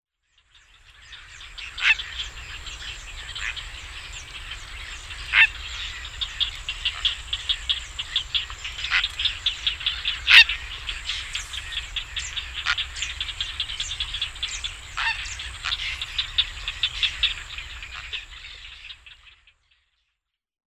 Ardea purpurea
Ardea-purpurea.mp3